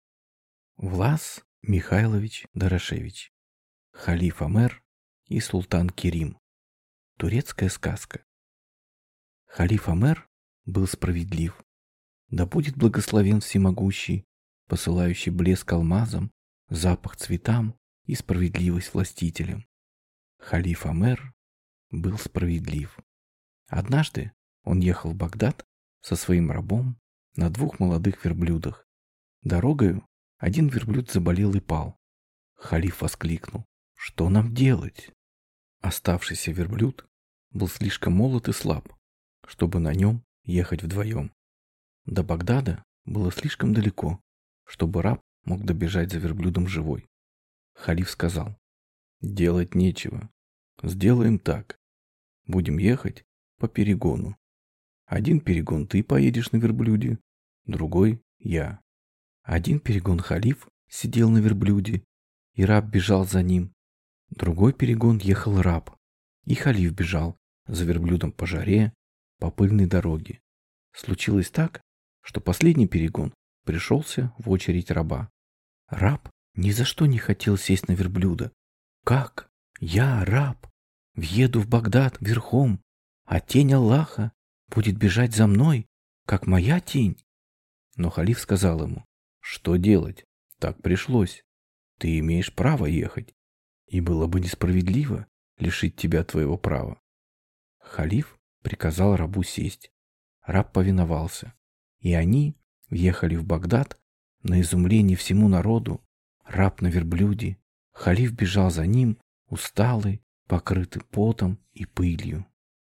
Запись произведена Аудио Издательством ВИМБО